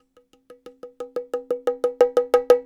Bongo Buildup 01.wav